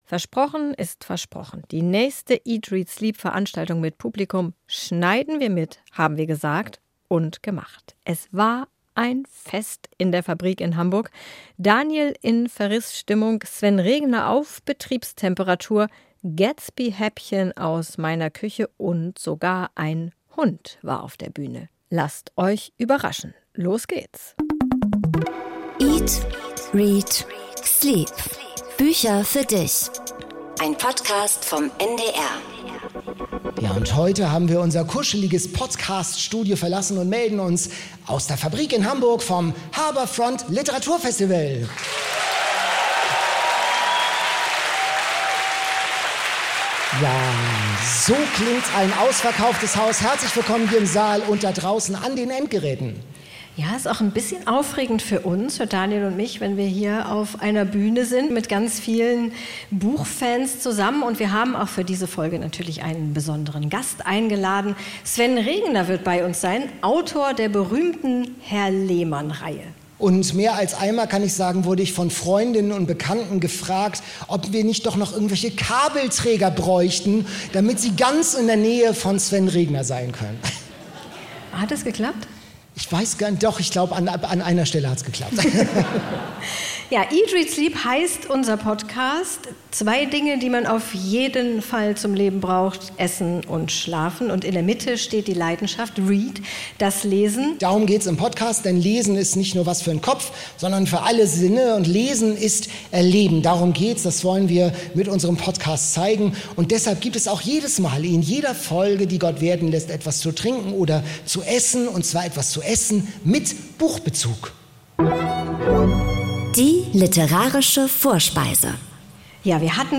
Beste Buch-Party-Stimmung bei der Show im Rahmen des Harbourfront Literaturfestivals.